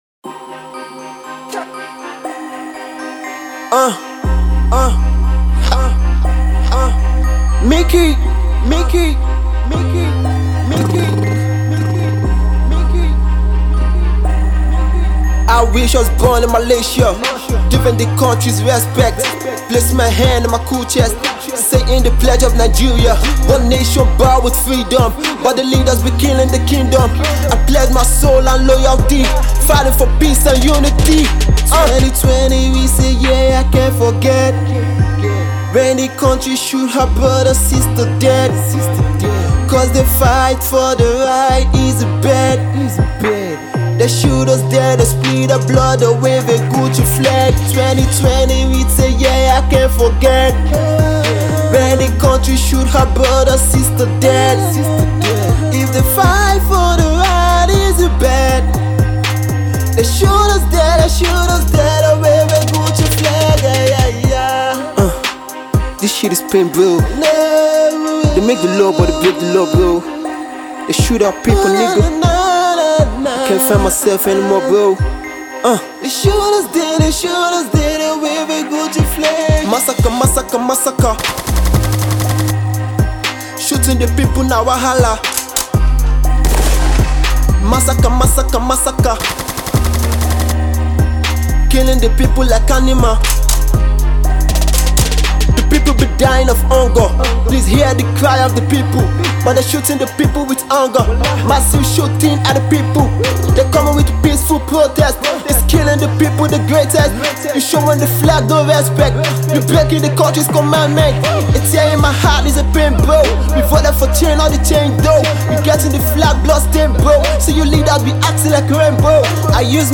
hit trap Vibez